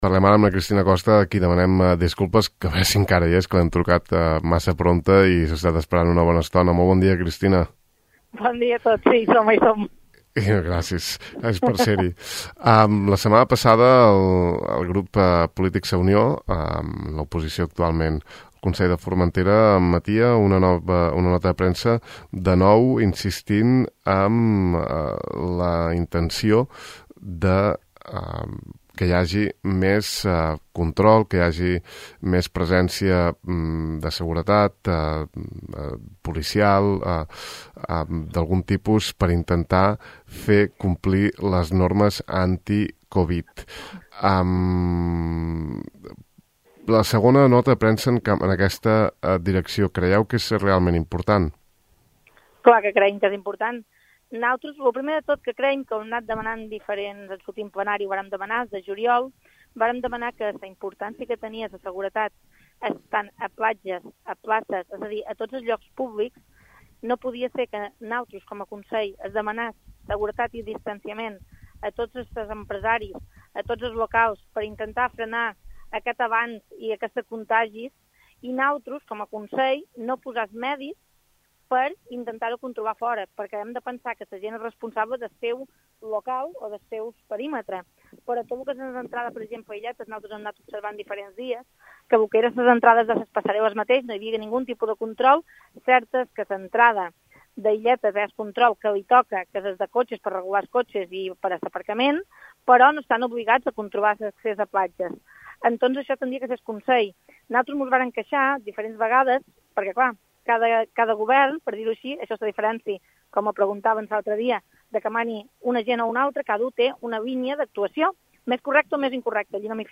Al De Far a Far conversam amb Cristina Costa, consellera de Sa Unió, després que aquesta formació hagi reiterat recentment la necessitat de dotar Formentera d’un servei de vigilància i seguretat privada de caràcter temporal per fer front a les mancances de forces de seguretat a l’illa. En parer de la consellera Costa, el Consell no ha sabut planificar aquesta temporada en termes de seguretat ciutadana davant la covid, deixant platges i places sense el pertinent control.